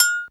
HR16B AGOGO.wav